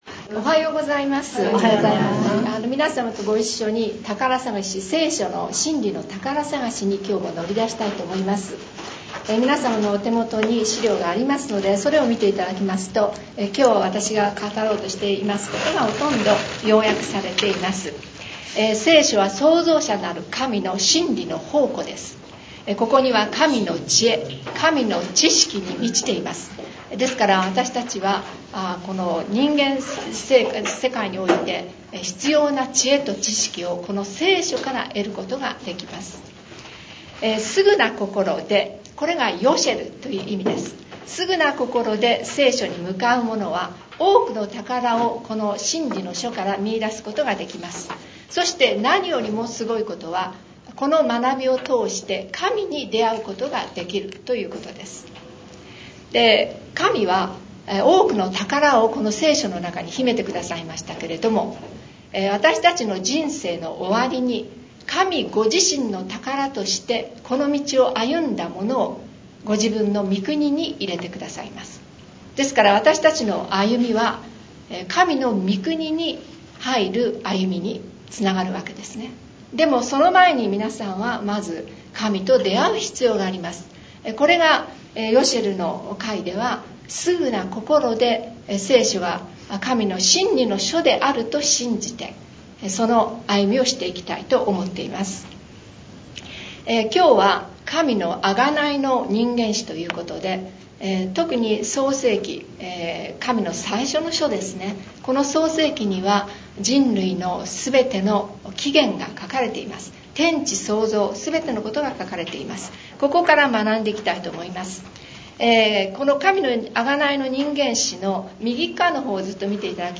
レポート 10月28日に第二回ヨシェルの会が開催されました。出席者は16名。